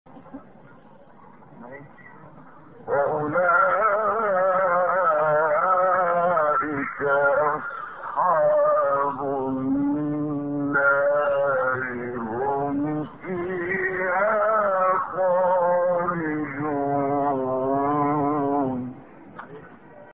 گروه فعالیت‌های قرآنی: فرازهایی در مقام صبا با صوت محمد عمران ارائه می‌شود.
برچسب ها: خبرگزاری قرآن ، ایکنا ، فعالیت های قرآنی ، مقام صبا ، محمد عمران ، قاری مصری ، فراز صوتی ، نغمه ، قرآن ، iqna